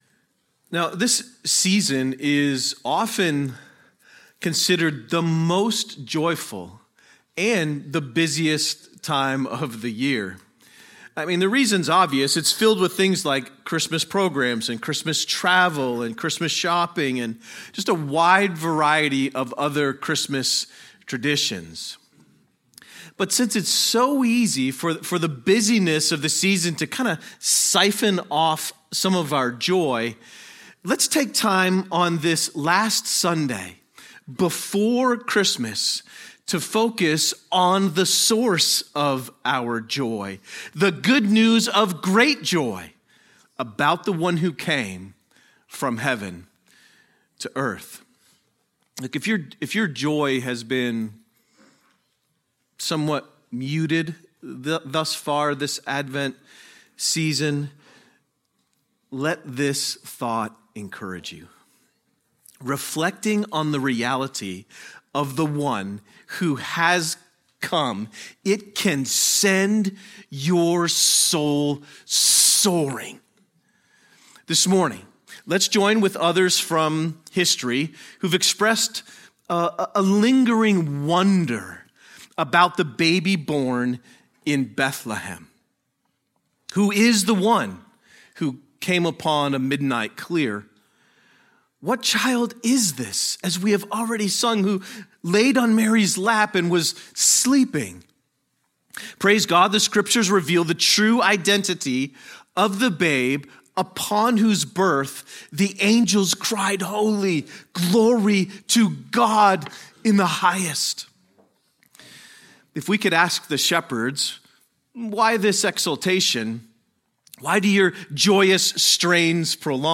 A sermon on John 1:1-5